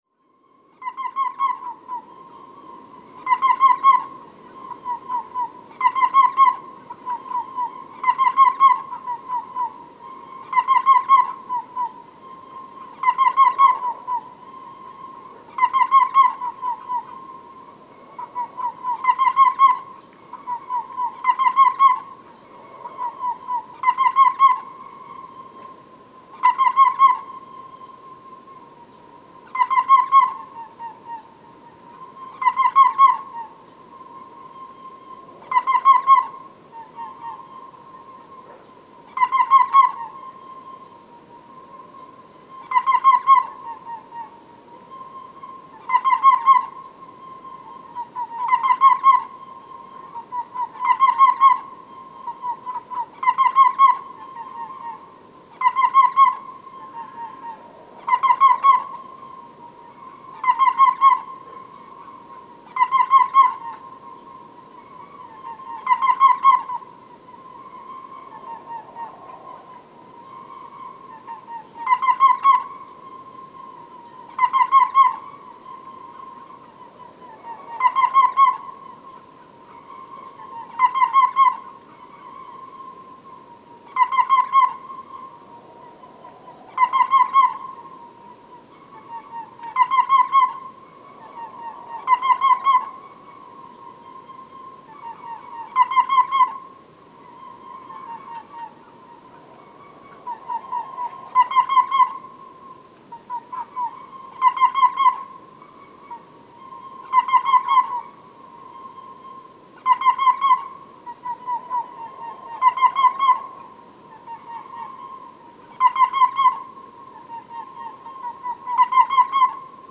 Here is a sampling of field recordings, compositions, and sonic ephemera from my studio.
Ancestral cricket song
Slow summer crickets